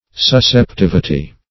Meaning of susceptivity. susceptivity synonyms, pronunciation, spelling and more from Free Dictionary.
Search Result for " susceptivity" : The Collaborative International Dictionary of English v.0.48: Susceptivity \Sus`cep*tiv"i*ty\, n. Capacity for receiving; susceptibility.